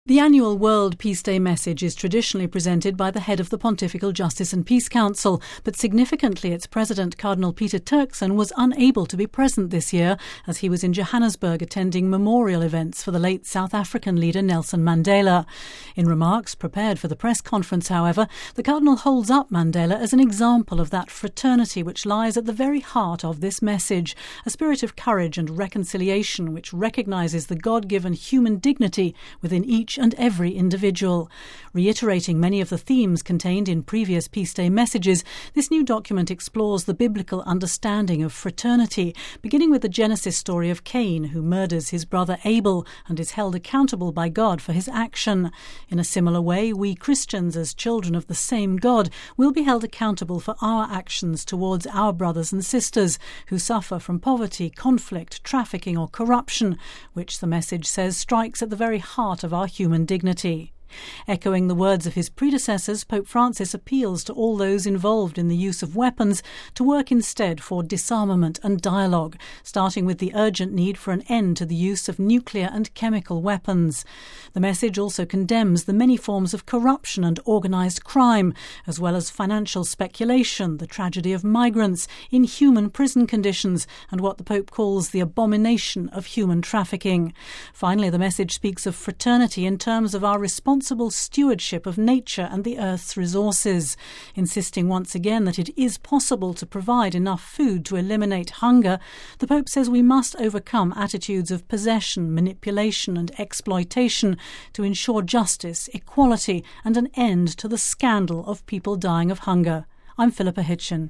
(Vatican Radio) At a press conference in the Vatican on Thursday, Pope Francis’ Message for the 2014 World Day of Peace was unveiled, focused on the theme ‘Fraternity: the Foundation and Pathway to Peace’.